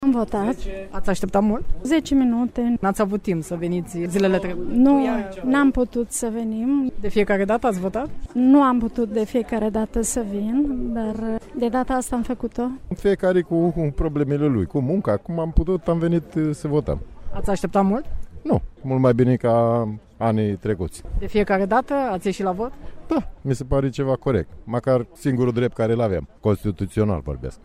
vox-Roma-alegeri-prezi-2019.mp3